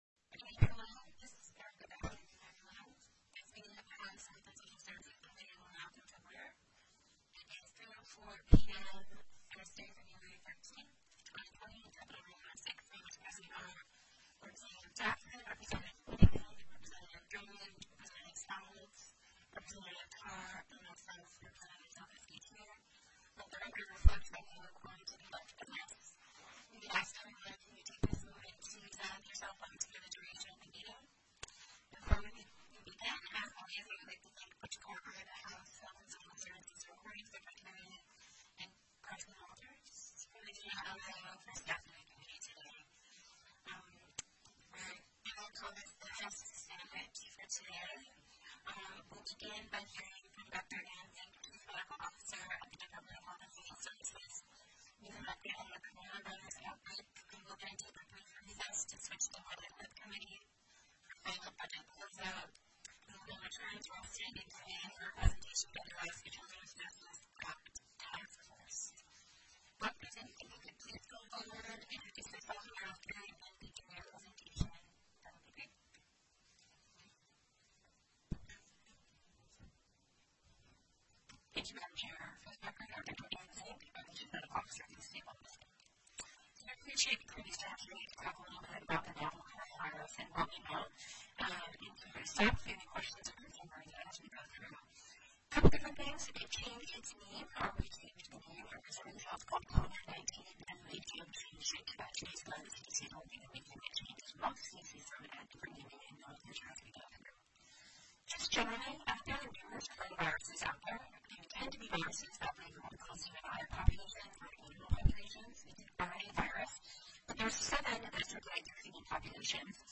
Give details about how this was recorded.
02/13/2020 03:00 PM House HEALTH & SOCIAL SERVICES The audio recordings are captured by our records offices as the official record of the meeting and will have more accurate timestamps.